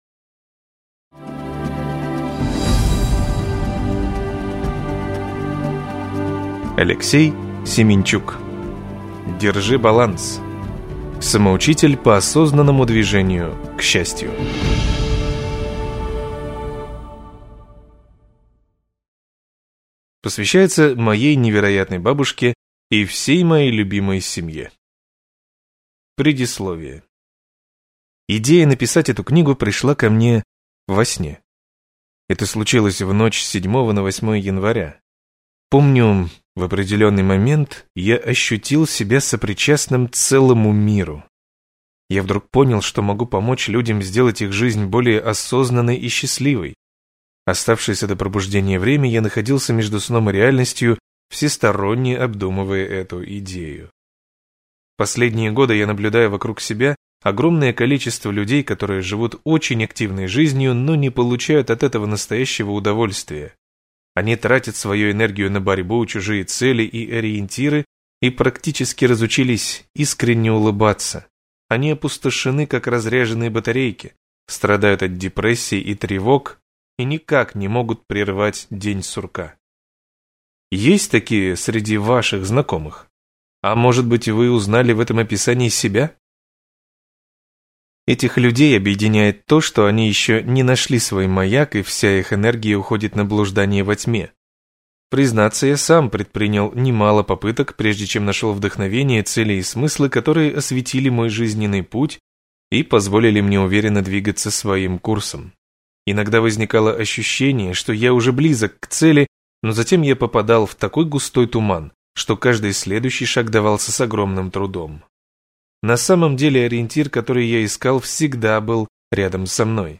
Аудиокнига Держи баланс! Самоучитель по осознанному движению к счастью | Библиотека аудиокниг